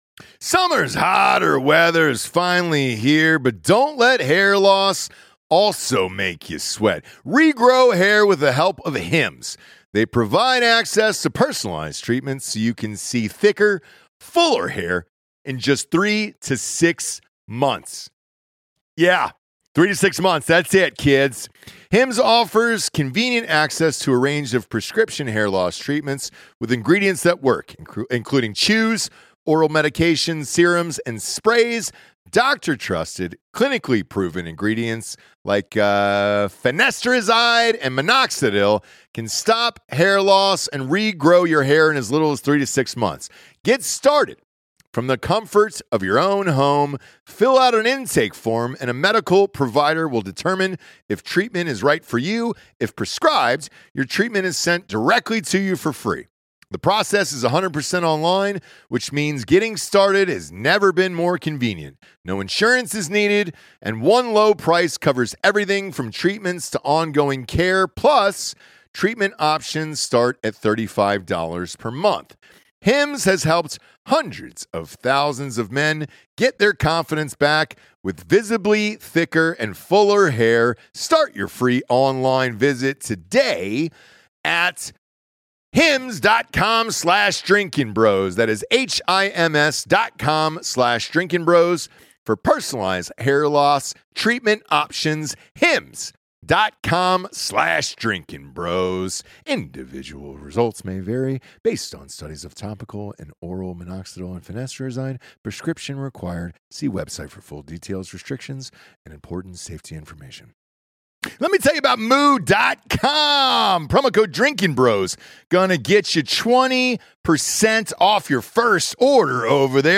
plus Jonathan does incredible impressions of Chris Hemsworth, Liam Neeson, Tom Hanks, Anthony Bourdain, and Wallace Shawn.